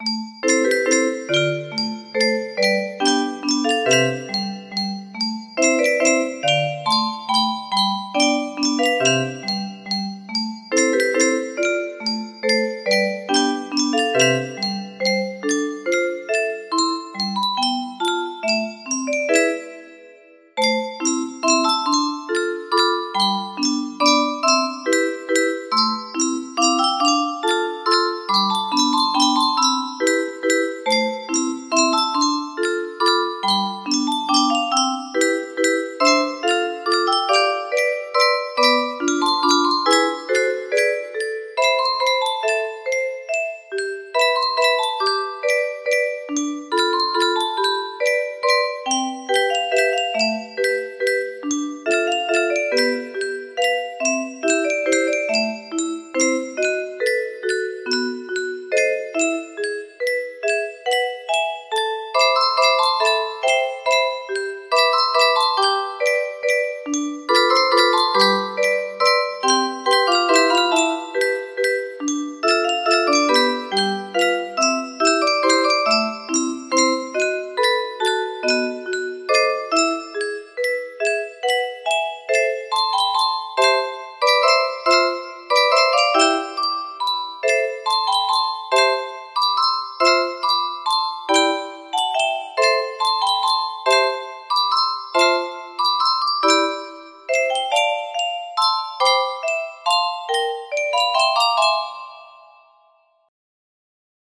BPM 70